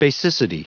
Prononciation du mot basicity en anglais (fichier audio)
Prononciation du mot : basicity